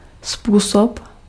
Ääntäminen
IPA : /weɪ/